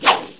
1 channel
staffswing.wav